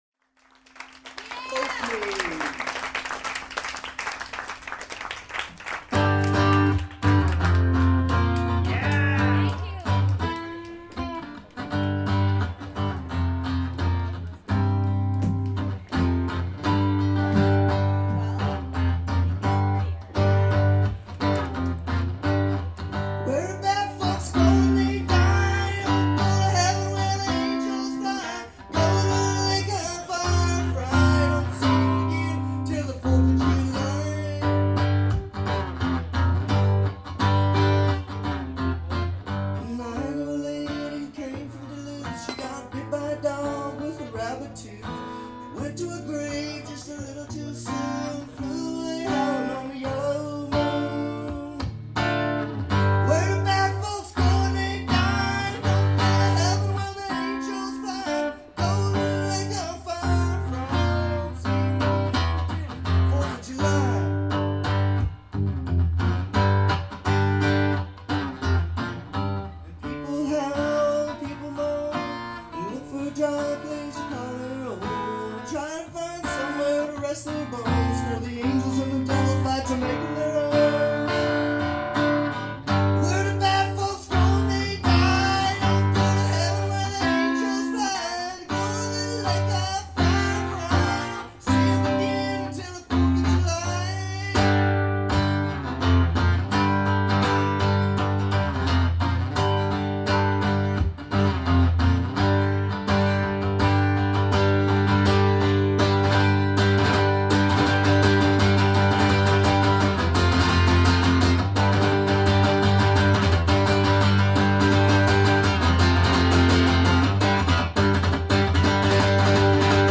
The quality is outstanding.